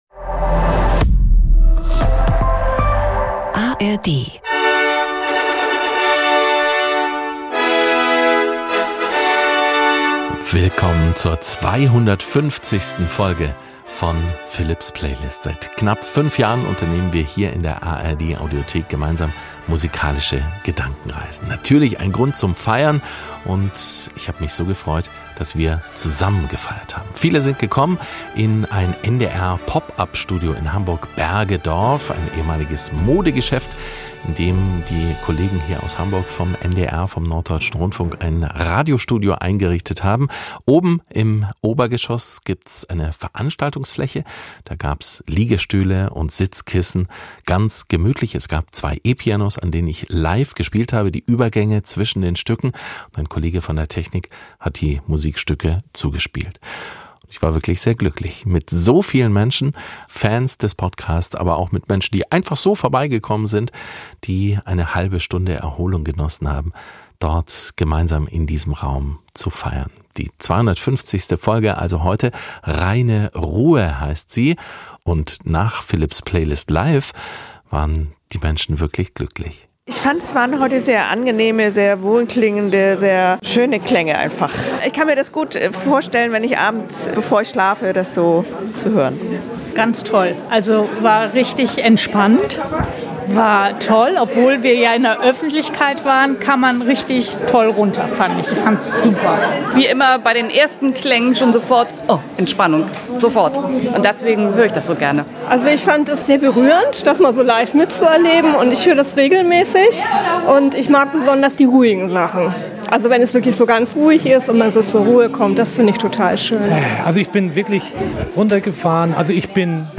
Es wurde gemeinsam gefeiert und zur Ruhe gekommen. So glücklich und entspannt klingt die Jubiläums-Playlist.